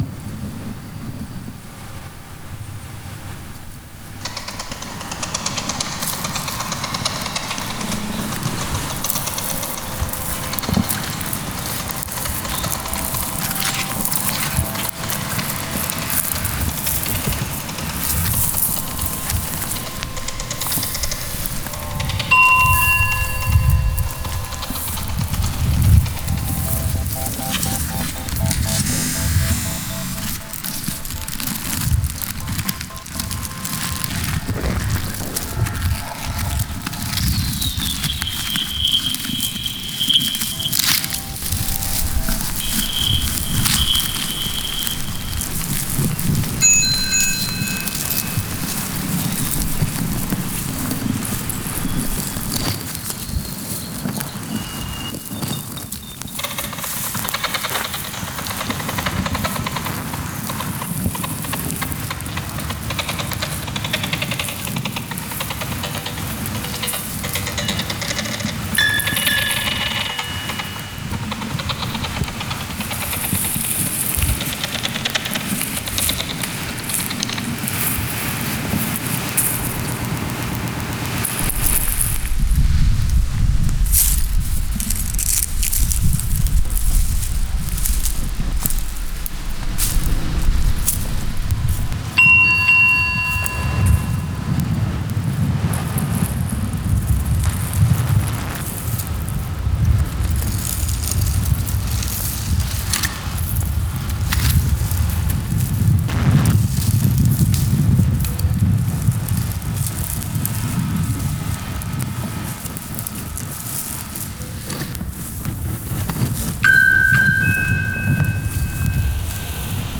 Deep and high-pitched frequencies are filled with beeps, scrapes, horns, wind whipping, crunching leaves underfoot, and tires on gravel.
From this starting point, we hear wind and industry, the crunch of brittle grasses and gravel. The tone of the field recording changes as we draw closer to the water. The wind begins to whoosh instead of whip, and we feel the dampening of sound but not yet the actual tone of water. Birds in trees, fishermen sitting in peace, mice squeaking in tall grasses.
My Sound Gathering process is as follows: about every 30 feet I take a sound map of a small area along my walk, which involves two to five minutes of listening with a sensitive field recording microphone.
In the Mary Meachum Freedom Crossing Sound Gathering shared here, sound assemblage points were taken on the Missouri side of the river proceeding toward the river’s edge.
Sound-Gathering_Meachum.wav